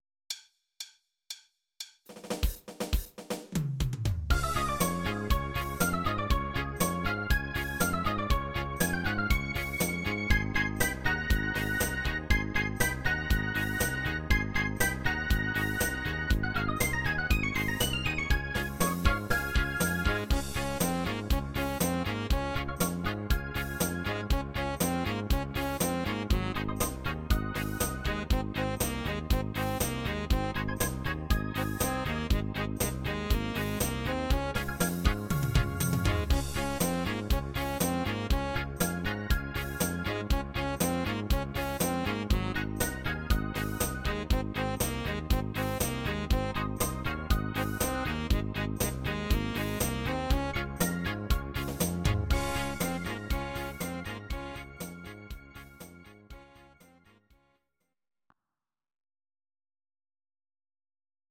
Audio Recordings based on Midi-files
Pop, Dutch, 1980s